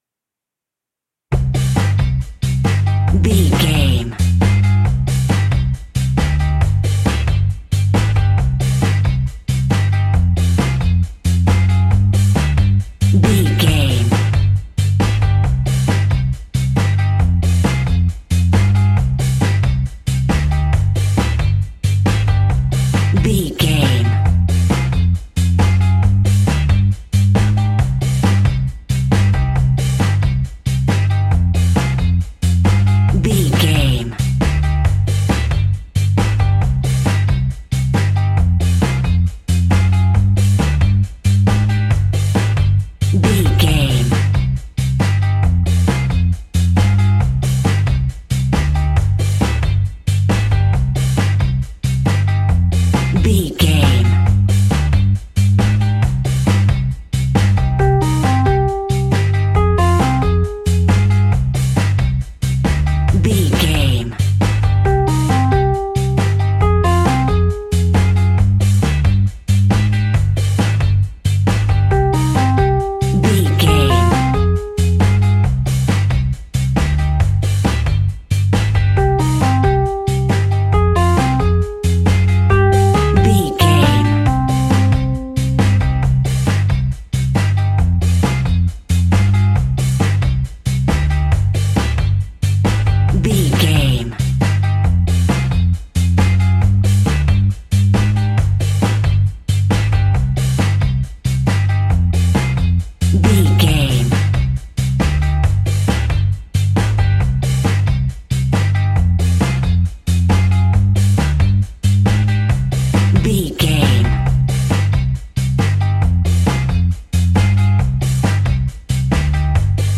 Classic reggae music with that skank bounce reggae feeling.
Aeolian/Minor
dub
instrumentals
laid back
chilled
off beat
drums
skank guitar
hammond organ
percussion
horns